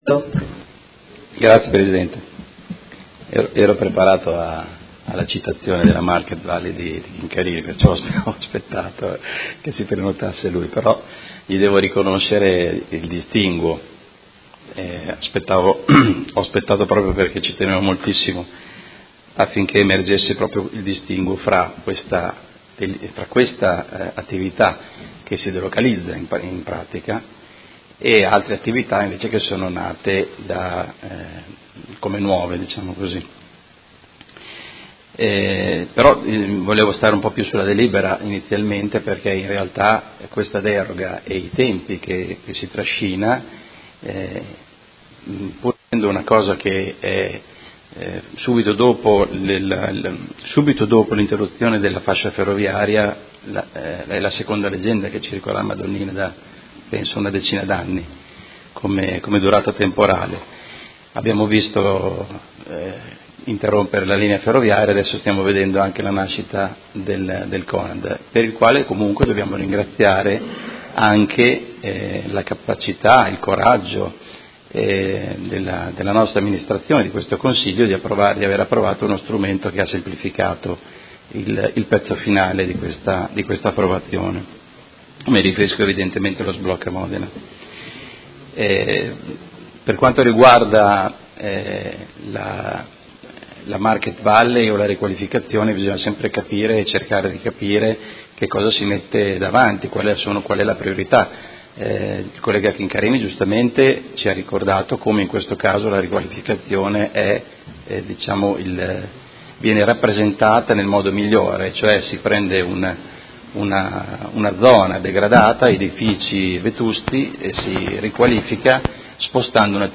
Carmelo De Lillo — Sito Audio Consiglio Comunale
Seduta del 12/10/2017 Dibattito.